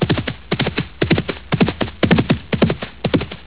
hear the pony run...
gallop.au